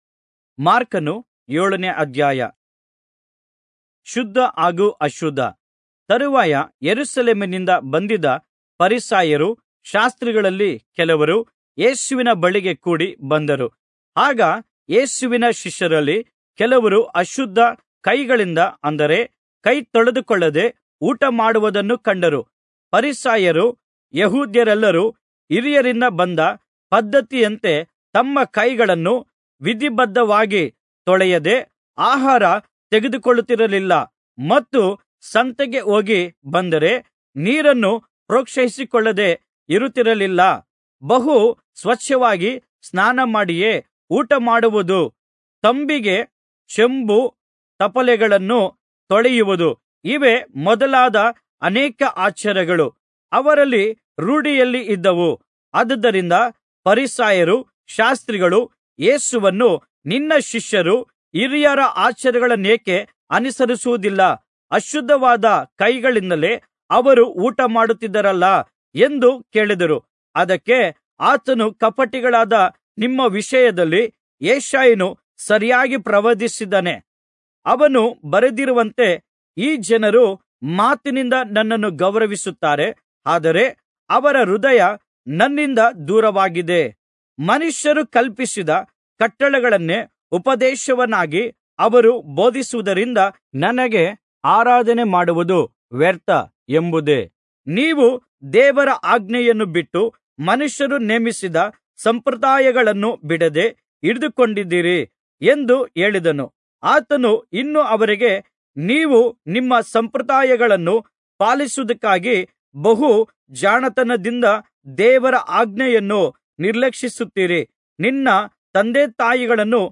Kannada Audio Bible - Mark 16 in Irvkn bible version